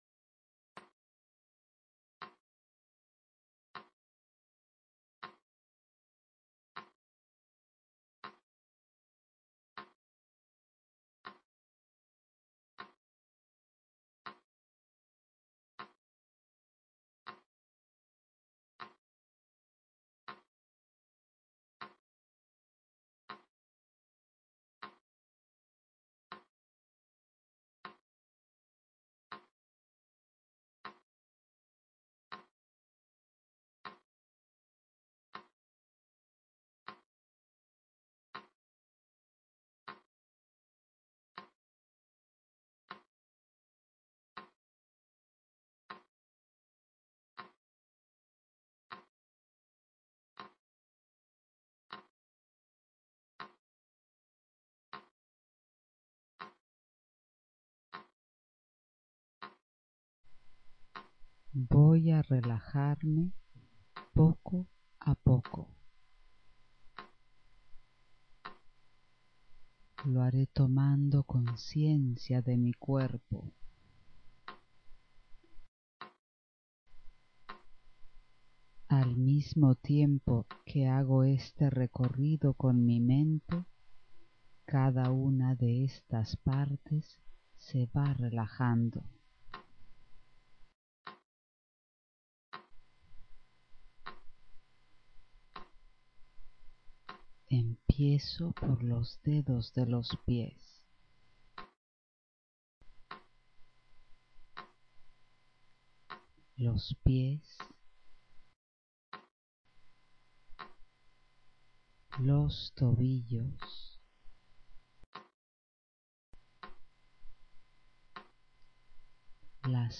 - Escúchalo con auriculares, ya que el sonido del tic tac va cambiando del oido derecho al izquierdo.